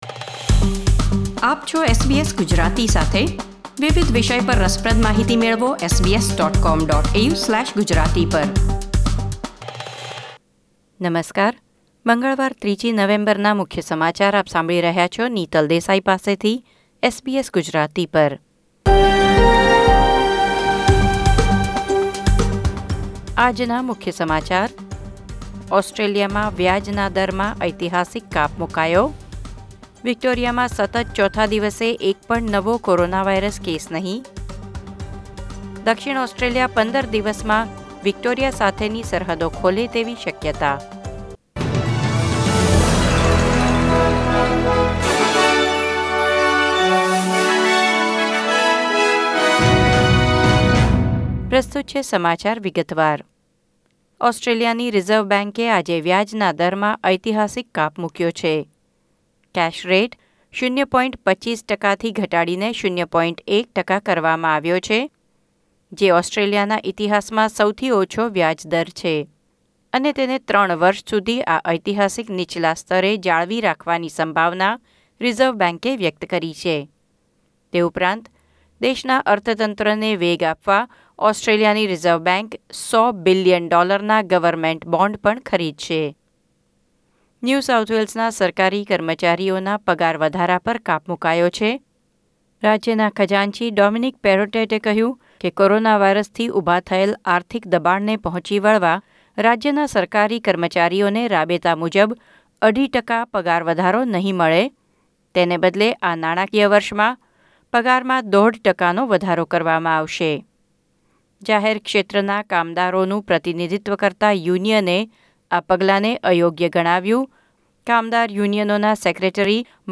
SBS Gujarati News Bulletin 3 November 2020